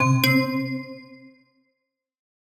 unlock_upgrade.wav